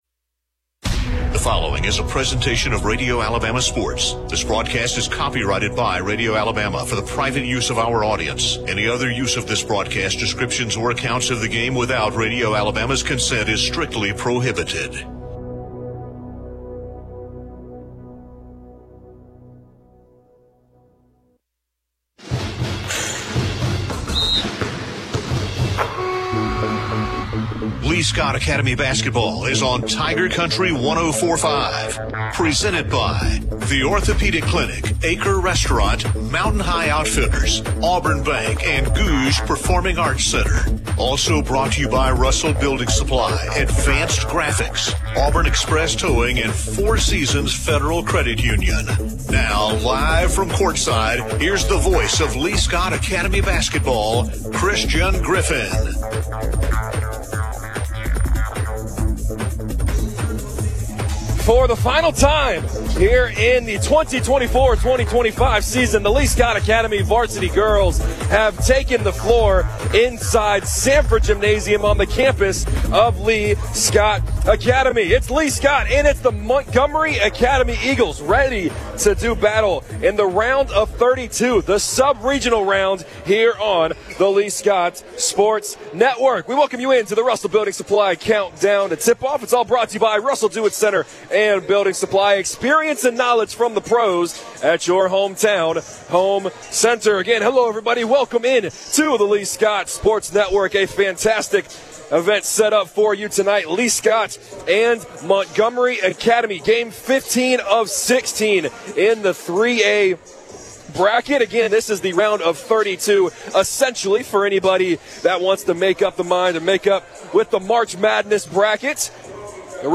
(Girls Basketball) Lee-Scott Academy vs. Montgomery Academy - Sub-Regional